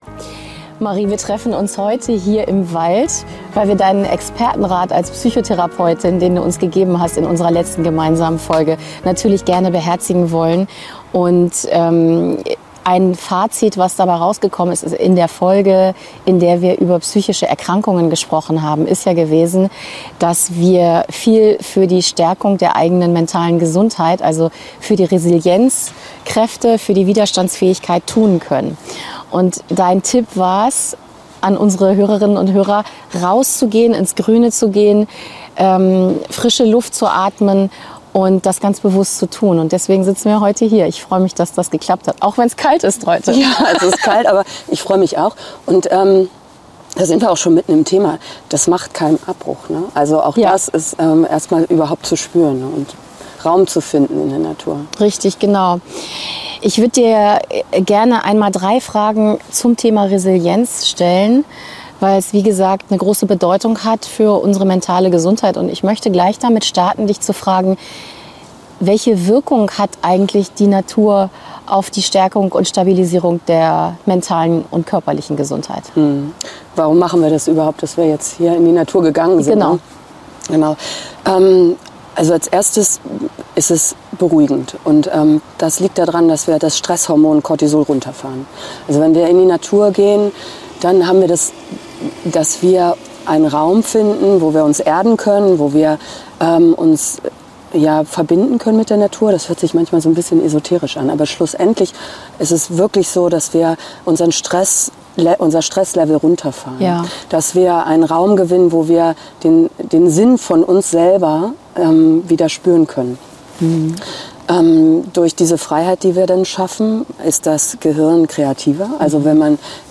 und zwar in unserer aktuellen LebensWert Folge direkt aus dem Wald.